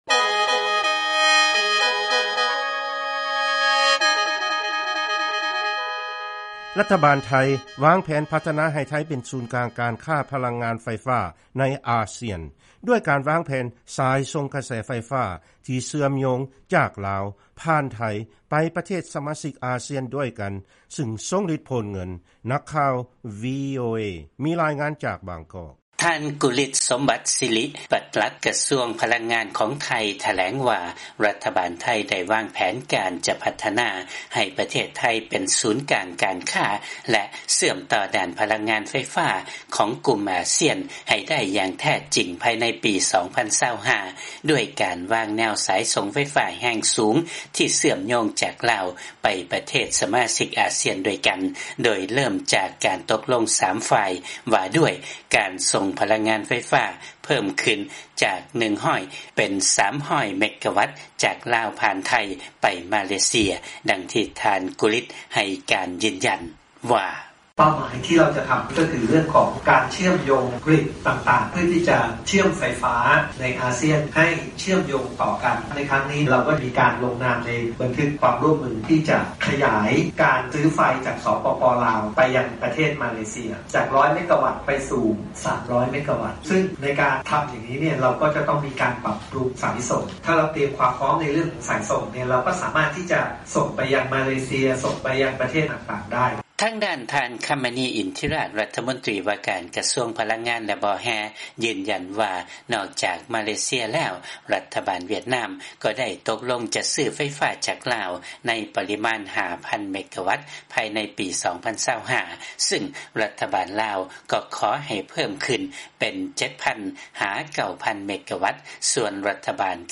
ຟັງລາຍງານ ລັດຖະບານ ໄທ ວາງແຜນ ພັດທະນາໃຫ້ ໄທ ເປັນສູນກາງການຄ້າ ພະລັງງານ ໄຟຟ້າໃນ ອາຊຽນ